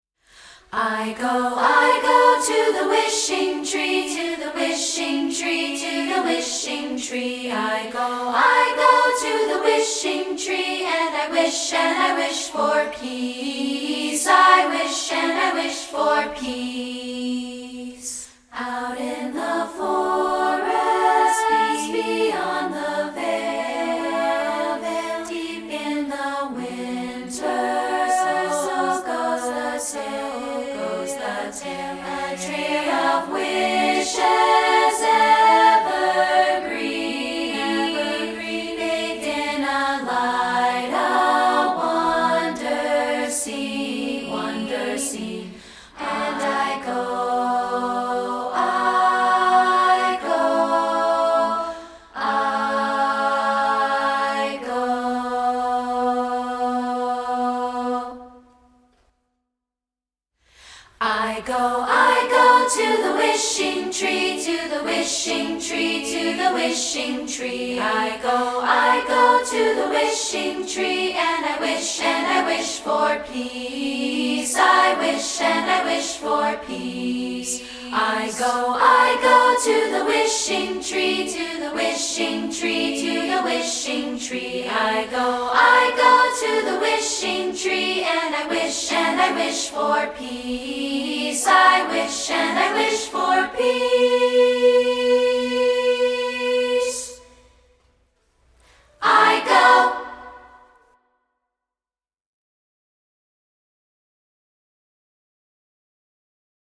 We're offering an a cappella recording of this song